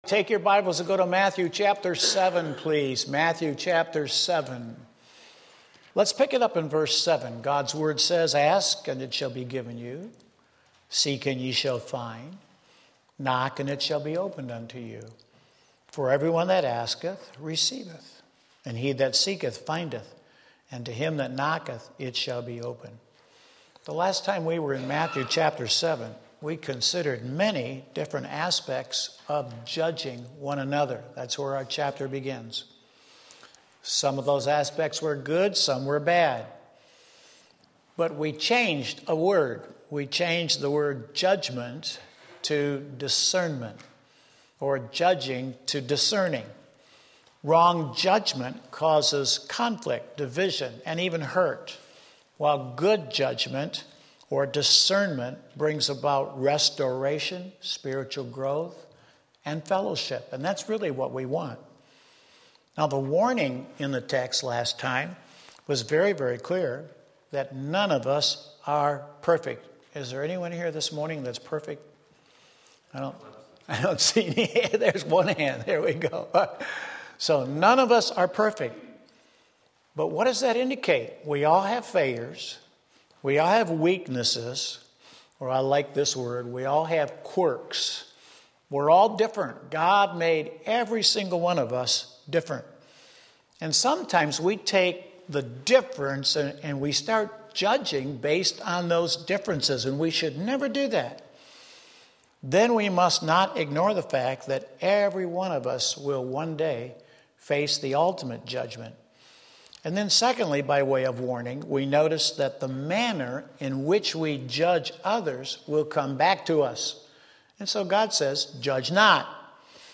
Stones and Serpents Matthew 7:7-12 Sunday School